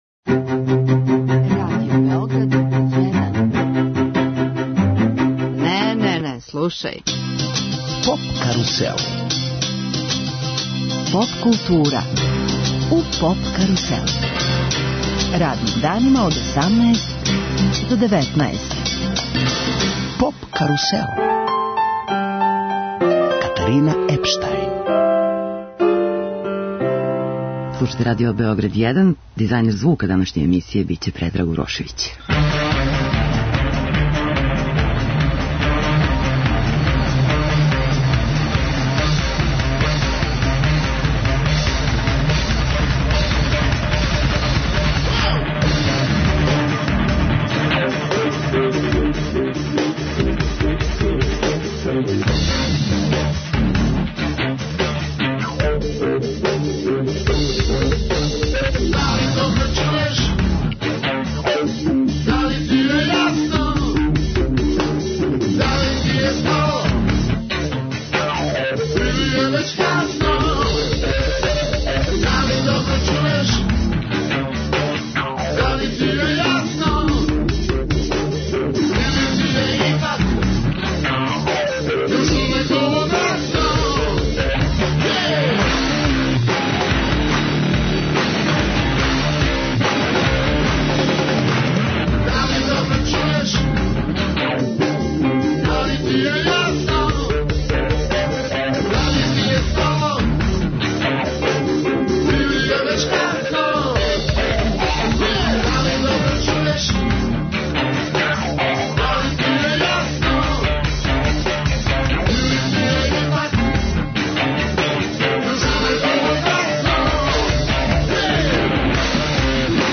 Гост емисије је Душан Којић Која, поводом концерта у Електропиониру, у суботу, формације Тресе.Лупа.Удара.